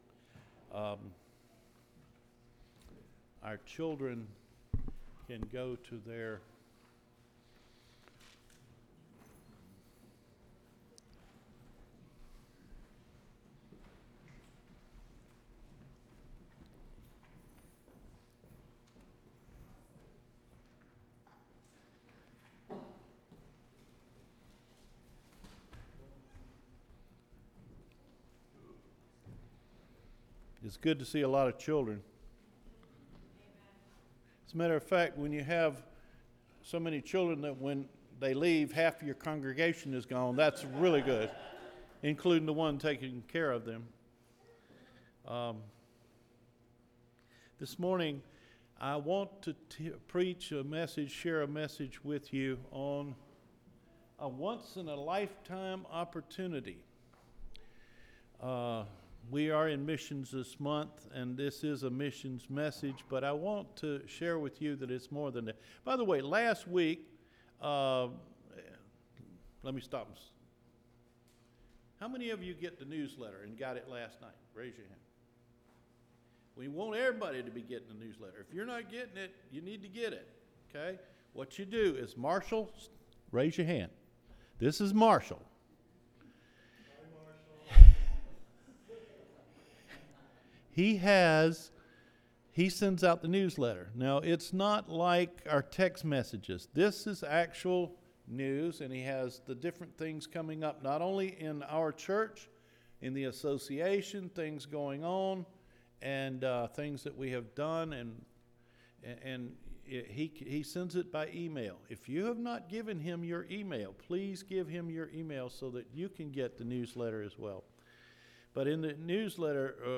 A ONCE IN A LIFETIME OPPORTUNITY – OCTOBER 13 SERMON